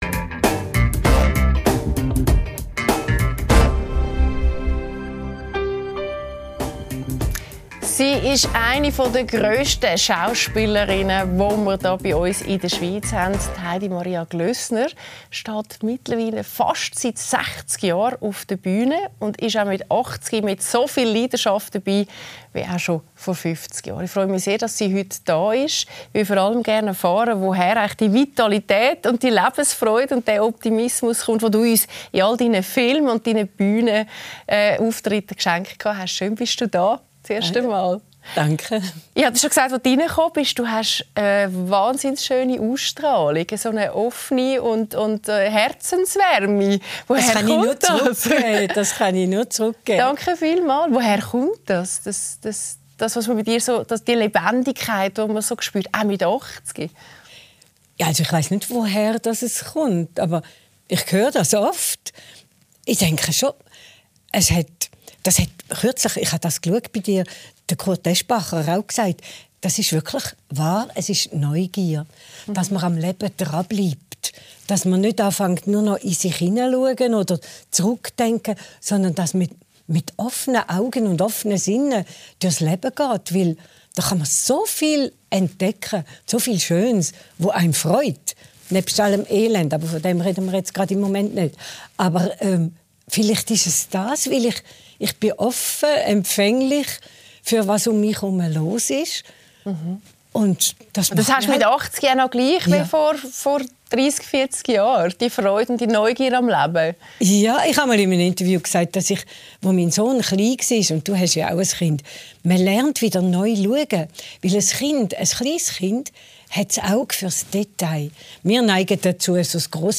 Mit Heidi Maria Glössner ~ LÄSSER ⎥ Die Talkshow Podcast
Die Schauspielerei ist ihr Leben: Auch mit über 80 Jahren steht Heidi Maria Glössner noch auf der Bühne und vor der Filmkamera. Mit Claudia Lässer spricht sie über ihre grosse Leidenschaft und erzählt, woher sie ihre Energie dafür nimmt.